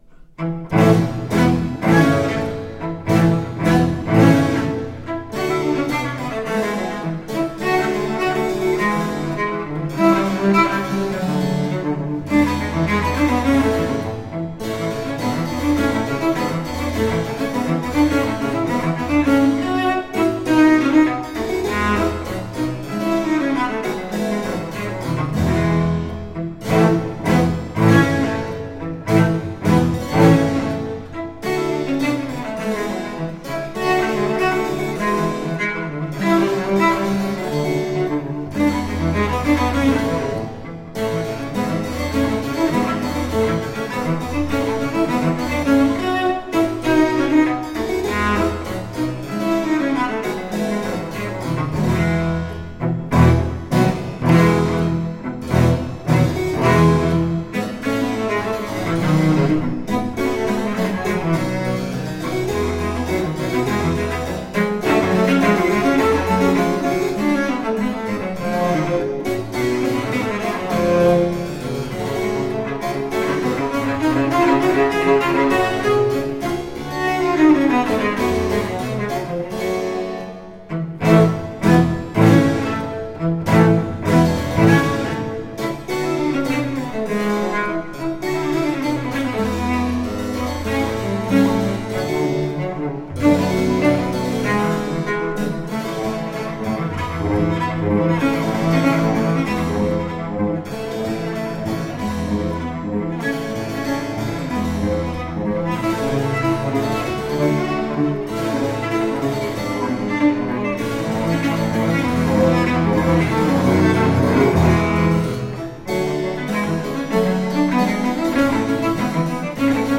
Rare and extraordinary music of the baroque.
Classical, Baroque, Instrumental
Harpsichord